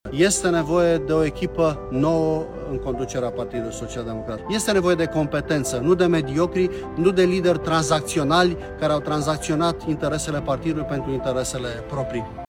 Într-un videoclip publicat pe pagina sa de Facebook, Titus Corlățean își anunță candidatura la președinția PSD. Acesta susține că partidul are nevoie de competență, nu de mediocritate.